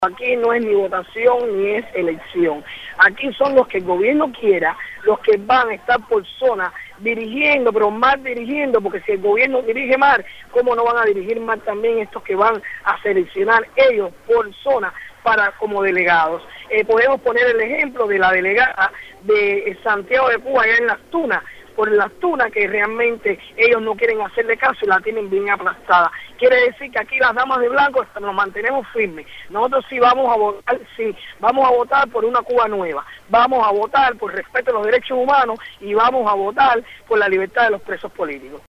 Soler dijo a Radio Martí, que en estas elecciones del Poder Popular “realmente no hay elecciones ni votación, son los que el Gobierno quiera”.